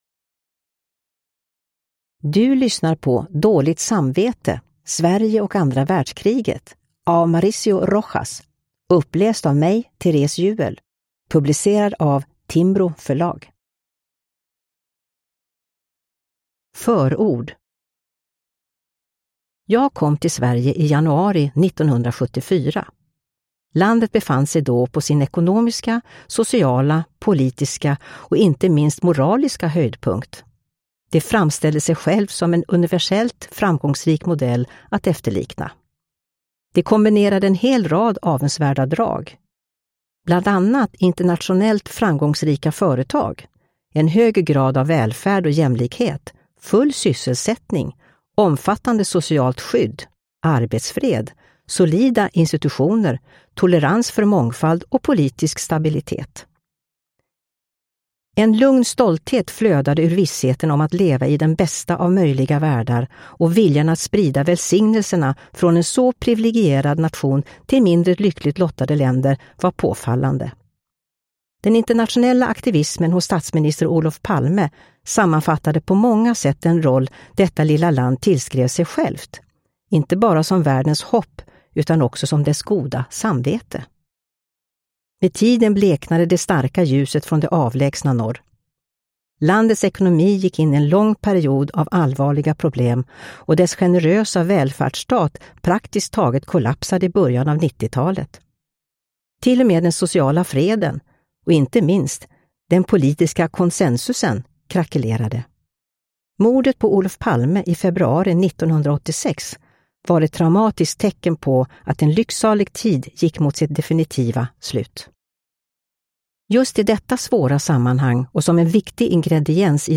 Dåligt samvete : Sverige och andra världskriget (ljudbok) av Mauricio Rojas